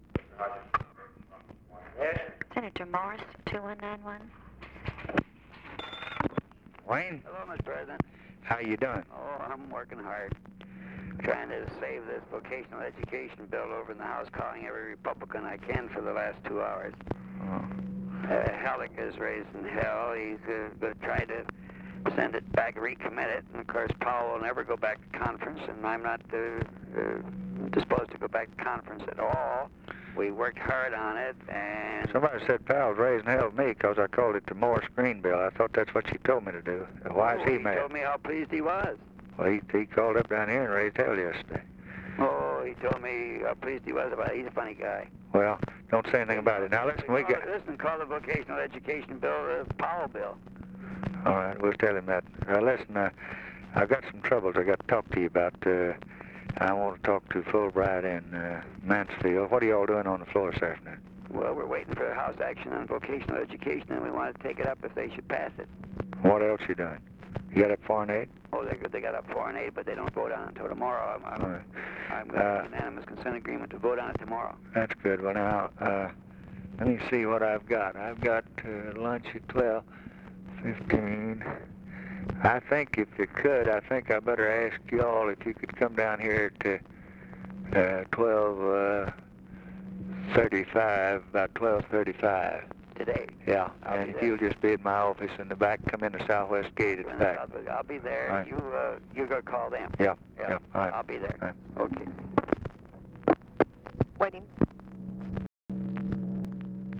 Conversation with WAYNE MORSE, December 12, 1963
Secret White House Tapes